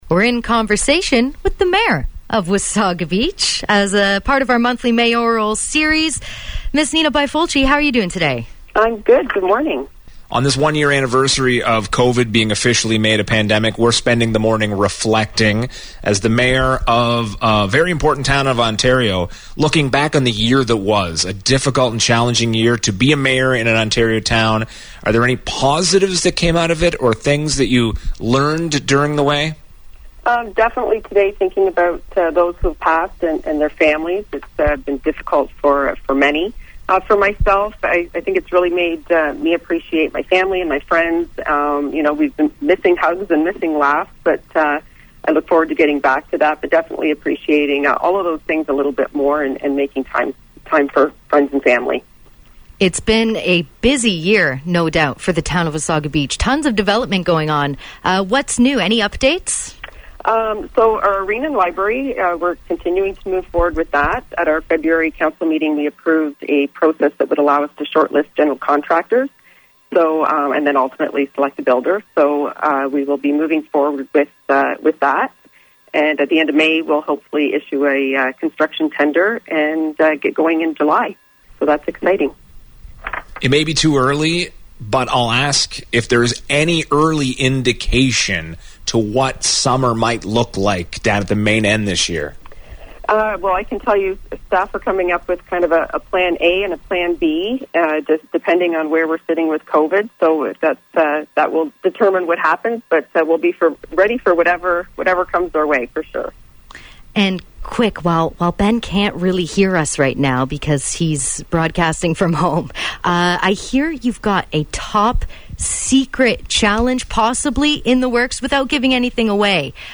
Another edition of our “Monthly Mayoral Series” with Wasaga Beach Mayor Nina Bifolchi, where she reflects back on the challenges & silver linings from one full year of Covid!